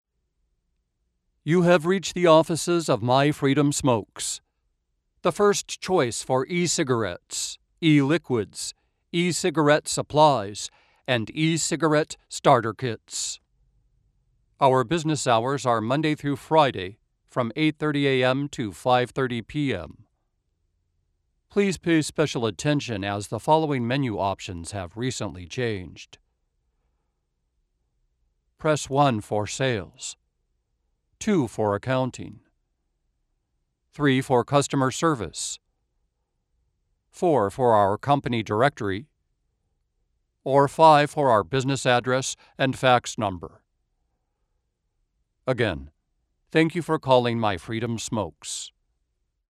My natural voice is very direct and forthright yet pleasing and approachable. I excel at high energy, multi-charactered voices from urban savvy to downright wacky!
Sprechprobe: Industrie (Muttersprache):